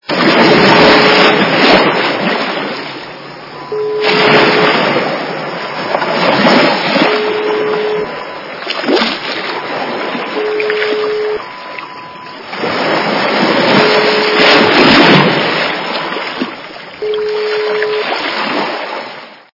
Океан или море - шум морского прибоя (шум прибоя) Звук Звуки Океан - Шум океана
» Звуки » Природа животные » Океан или море - шум морского прибоя (шум прибоя)
При прослушивании Океан или море - шум морского прибоя (шум прибоя) качество понижено и присутствуют гудки.